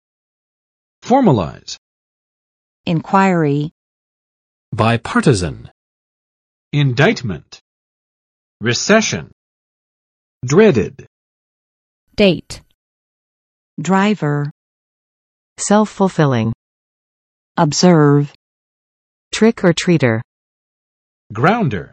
[ˋfɔrm!͵aɪz] v. 正式确定